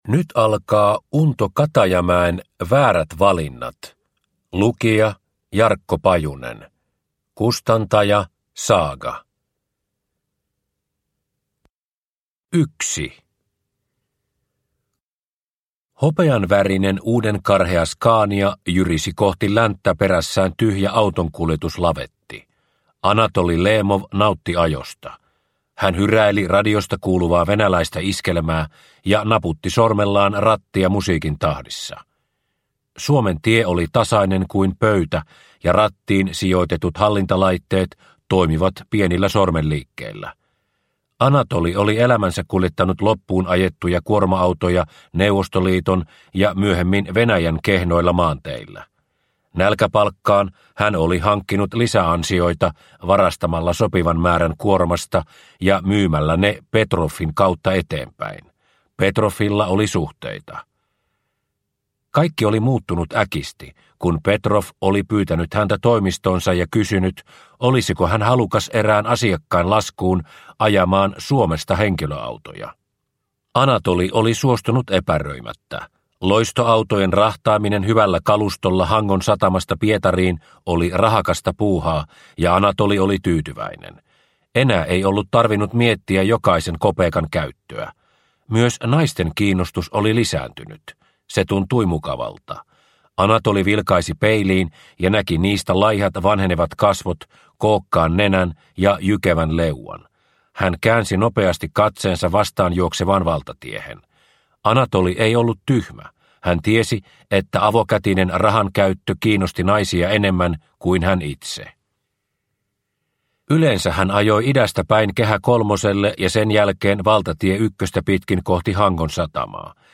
Väärät valinnat (ljudbok) av Unto Katajamäki